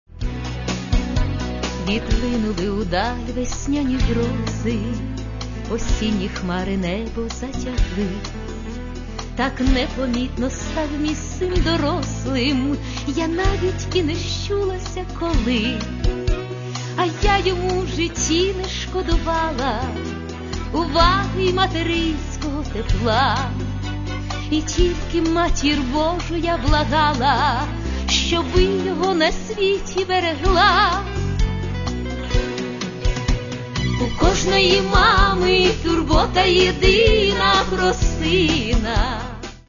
Каталог -> Эстрада -> Певицы